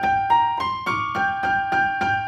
Index of /musicradar/gangster-sting-samples/105bpm Loops
GS_Piano_105-G2.wav